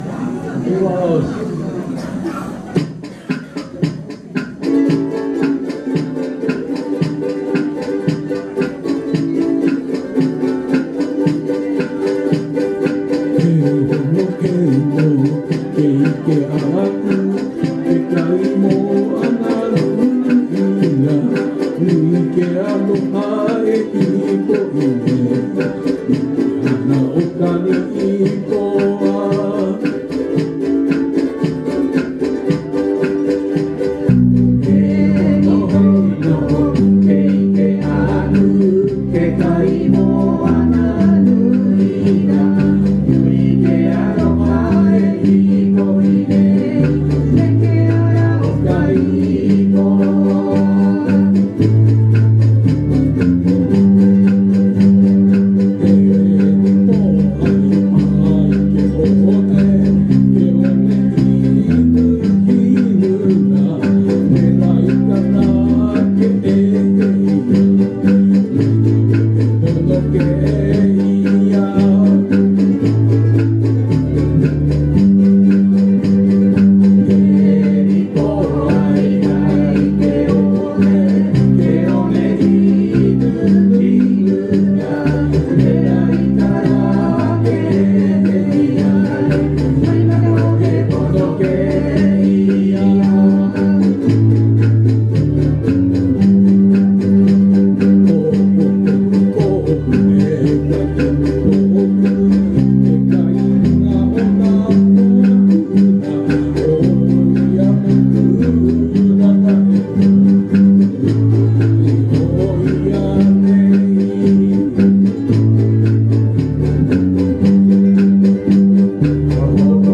ウクレレCDU ハワイアンX'masパーティー